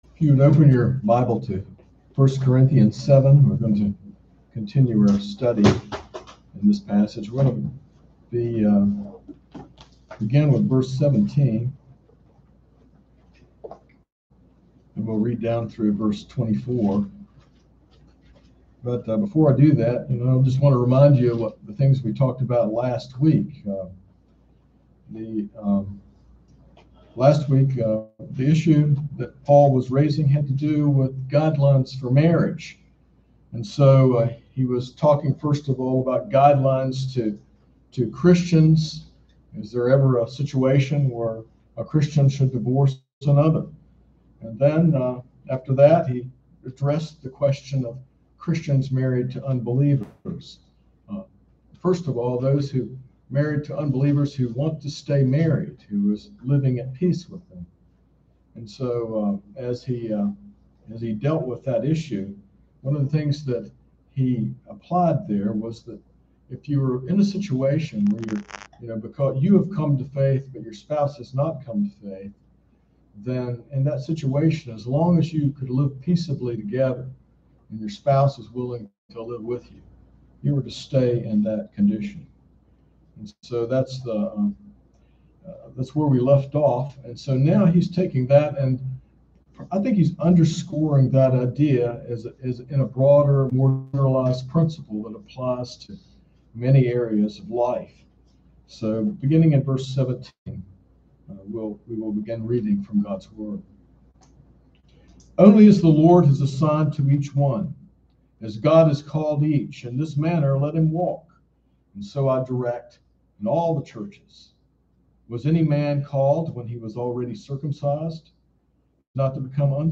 This sermon from 1 Corinthians 7 emphasizes remaining in the life circumstances God called you in, serving faithfully and finding contentment in your current status, whether married, unmarried, circumcised, uncircumcised, free, or enslaved.
sermon-8-1-21.mp3